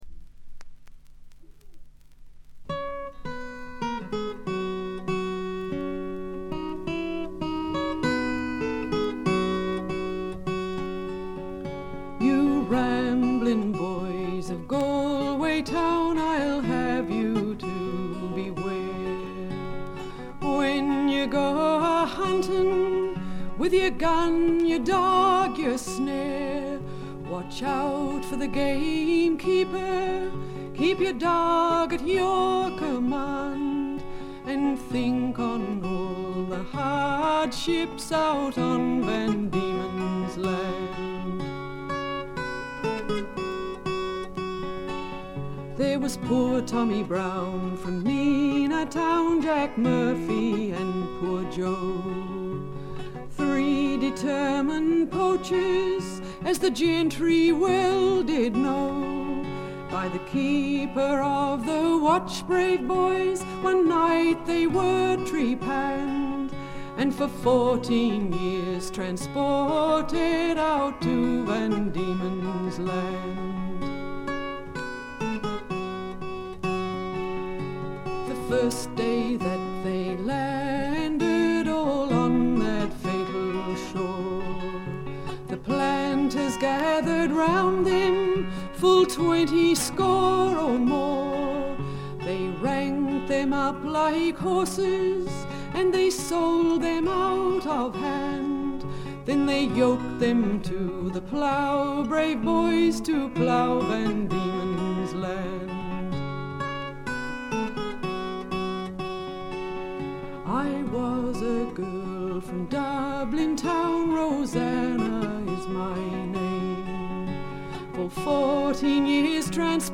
これ以外は軽微なバックグラウンドノイズやチリプチ程度。
試聴曲は現品からの取り込み音源です。
5-String Banjo
Bass [String Bass]